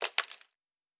Plastic Foley 04.wav